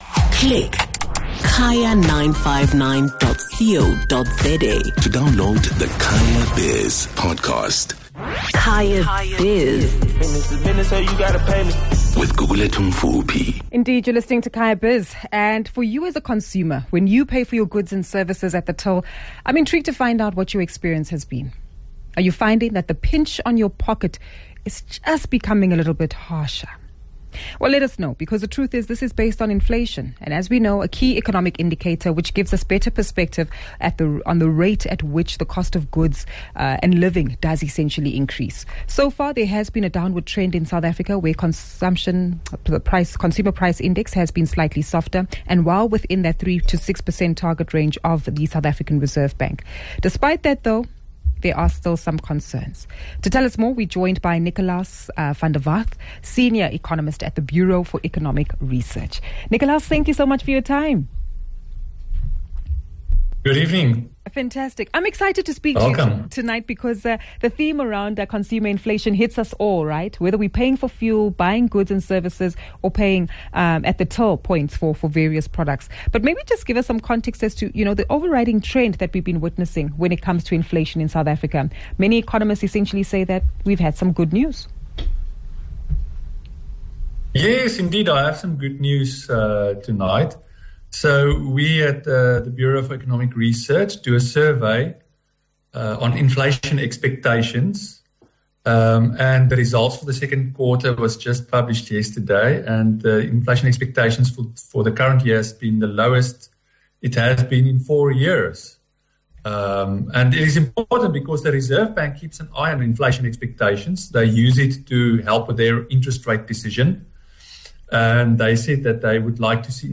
… continue reading 172 епізодів # Trading # Business News # Business # News # South Africa # Kaya FM